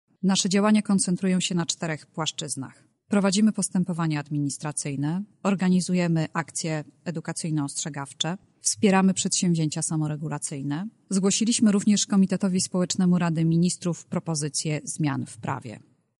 O tym, w jaki sposób UOKiK walczy z nielegalną sprzedażą mówi Dorota Karczewska, wiceprezes instytucji.